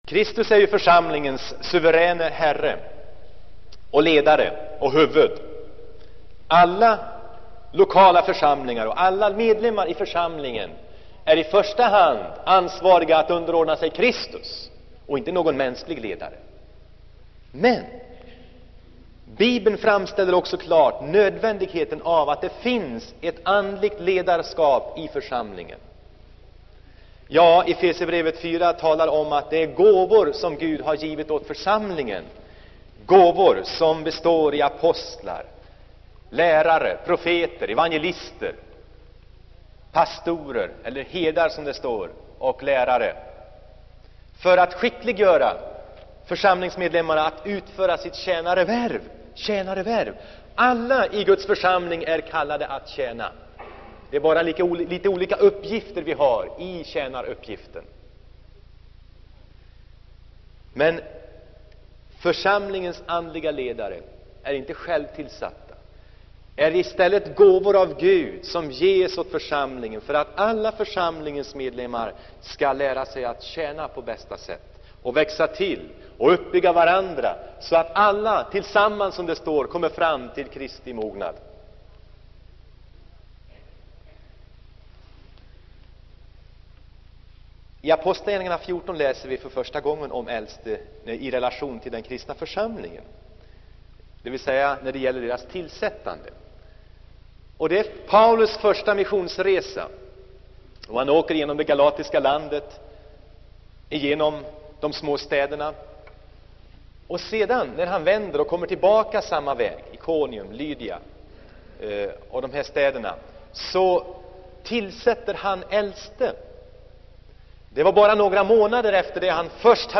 Inspelad i Saronkyrkan, Göteborg 1976-12-19. Predikan får kopieras och spridas men inte läggas ut på nätet, redigeras eller säljas.